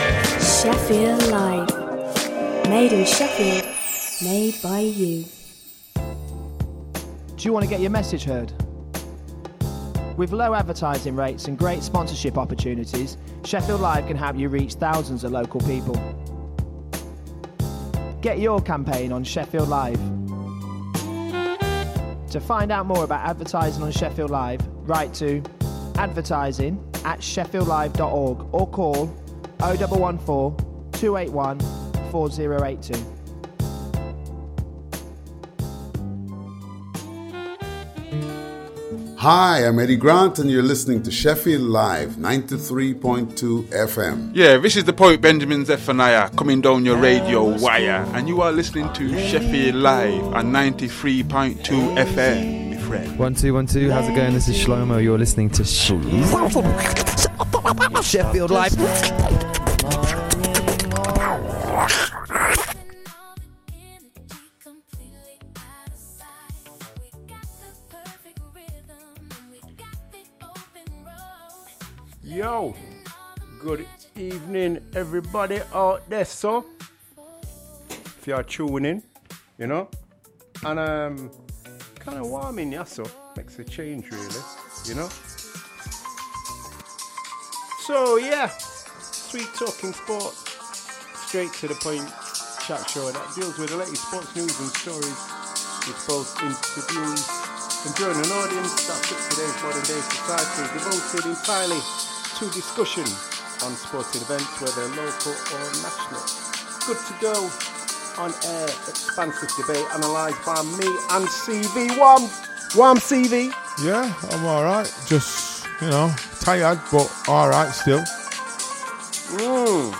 Talking Balls is a tongue in cheek sports chat show looking at the latest sports news and stories, with both interviews, previews and competitions